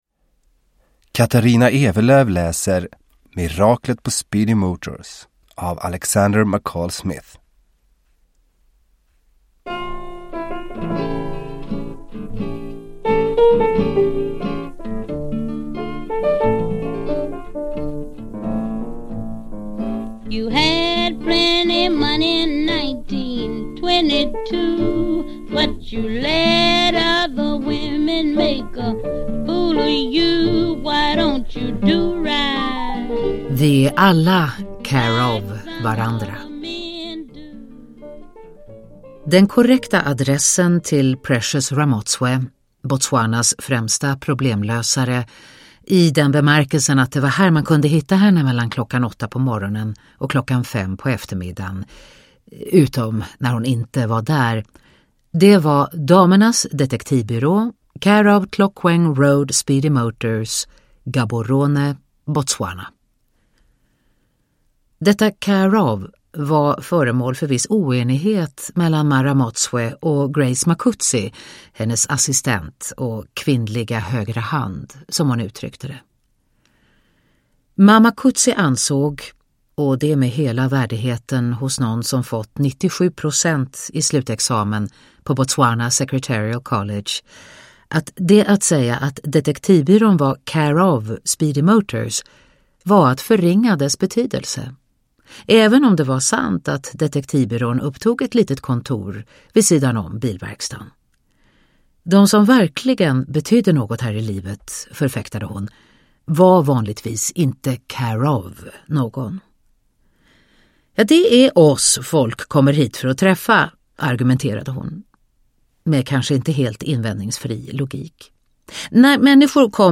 Miraklet på Speedy Motors – Ljudbok – Laddas ner
Uppläsare: Katarina Ewerlöf